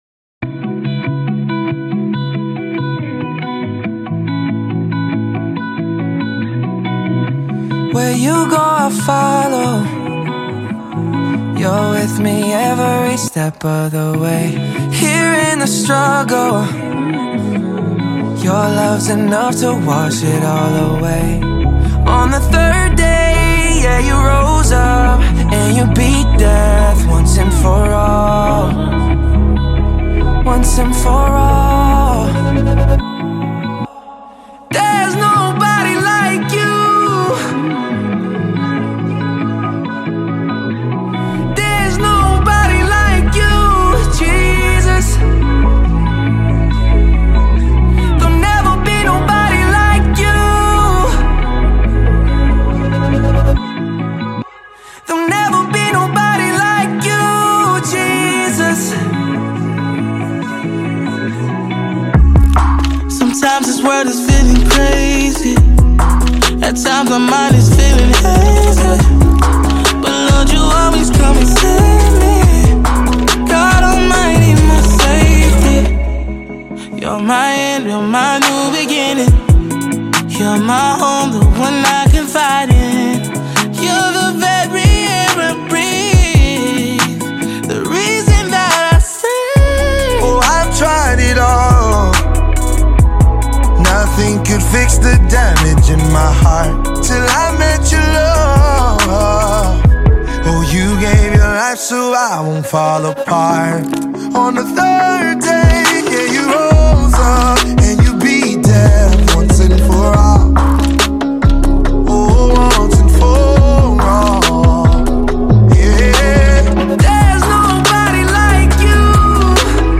powerful, soul-lifting song